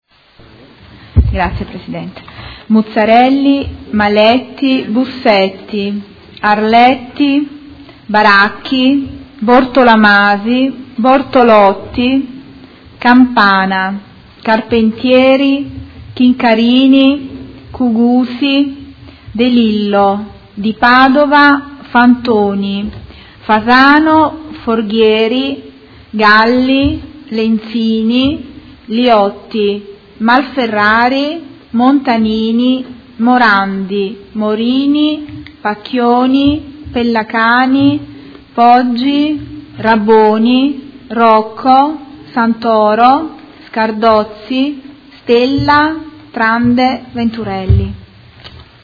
Appello
Segretario Generale